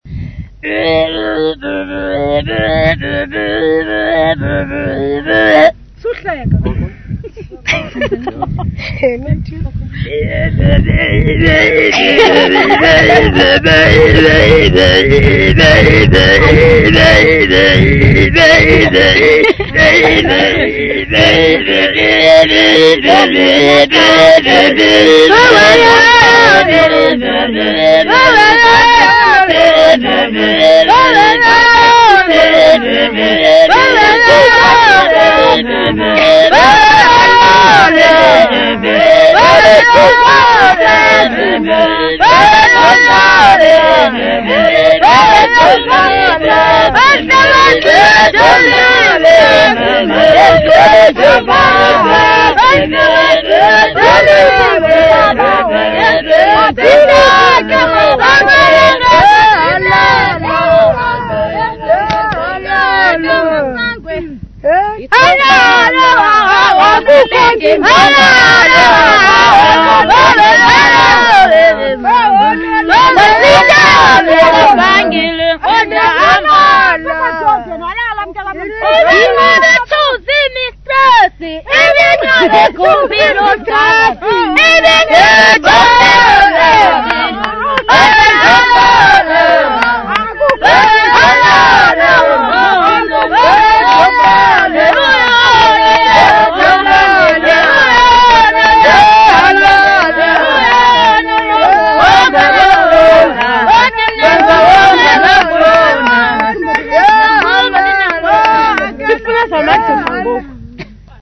Xhosa Choir Group
Folk music
Field recordings
sound recording-musical
Traditional song with Umngqokolo techniques
96000Hz 24Bit Stereo